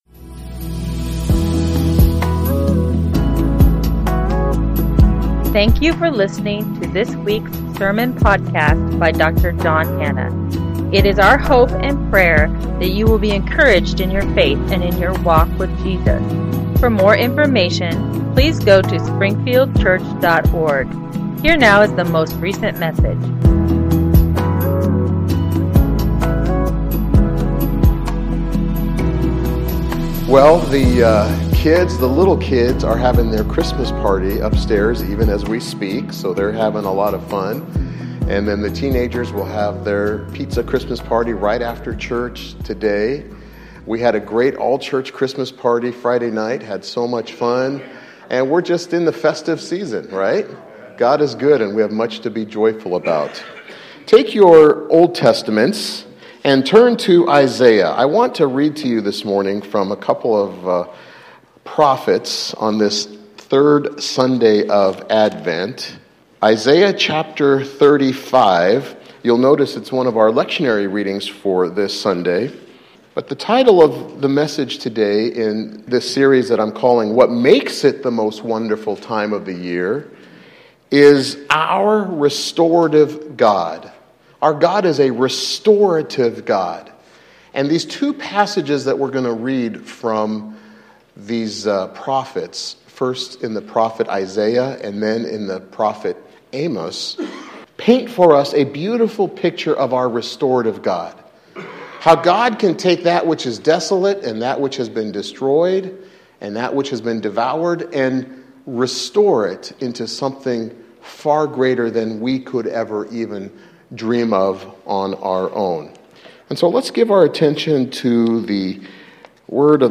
Not everyone delights in this time of year, nor will they seek the good associated with it. Yet God always has the last word when it comes to accomplishing His good and perfect will [note: sermon has been edited to 28 minutes for podcast]